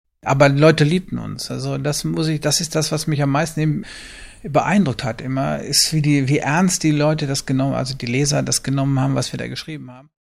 Dazu führte er zahlreiche Interviews mit der letzten Generation der SOUNDS-Redakteure durch und schnitt die Interviews entlang der Geschichte der SOUNDS zusammen.